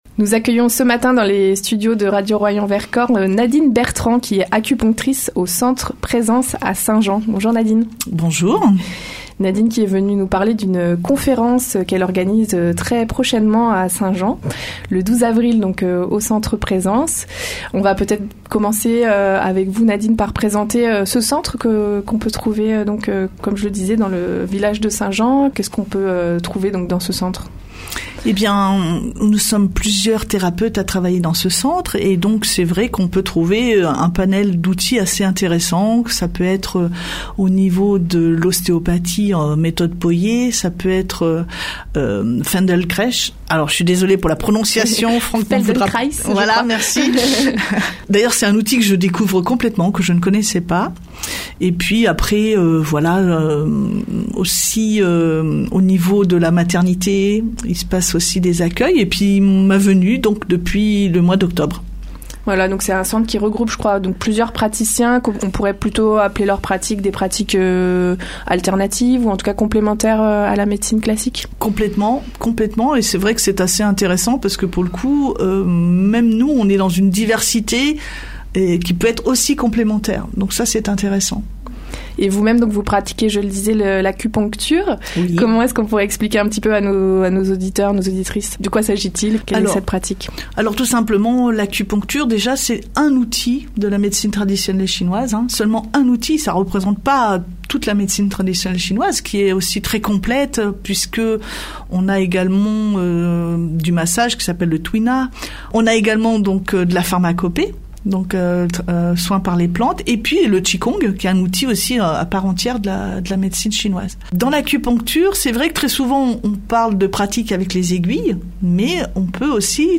Elle détaille cette proposition à notre micro.